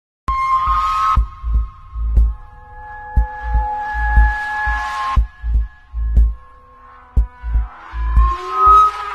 Linux Penguin Error Botão de Som